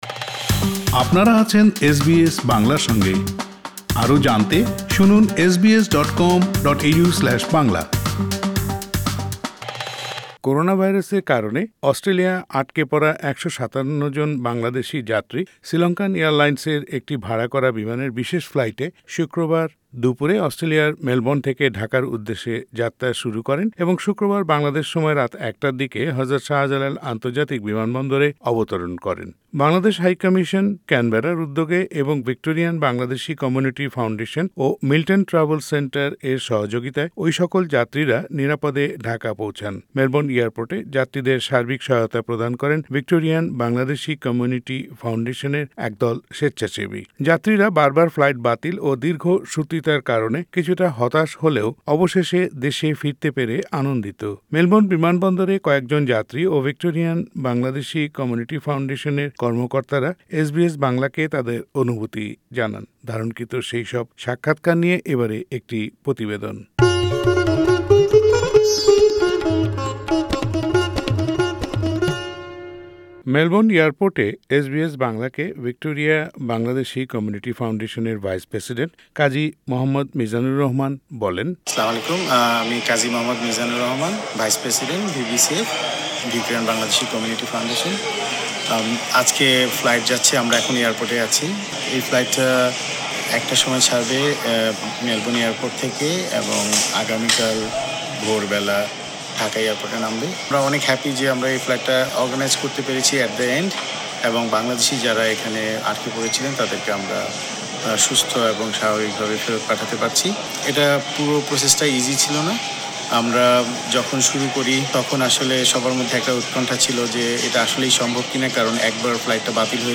যাত্রীরা বার বার ফ্লাইট বাতিল ও দীর্ঘ সূত্রিতার কারণে কিছুটা হতাশ হলেও অবেশে দেশে ফিরতে পেরে আনন্দিত। মেলবোর্ন বিমানবন্দরে কয়েকজন যাত্রী ও ভিক্টোরিয়ান বাংলাদেশি কমিউনিটি ফাউন্ডেশন এর কর্মকর্তারা এস বি এস বাংলা কে তাদের অনুভূতি জানান। ধারণকৃত সাক্ষাৎকার গুলো শুনতে উপরের অডিও প্লেয়ারের লিংকটিতে ক্লিক করুন।